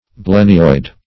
blennioid - definition of blennioid - synonyms, pronunciation, spelling from Free Dictionary
Blennioid \Blen"ni*oid\, Blenniid \Blen"ni*id\, a. [Blenny +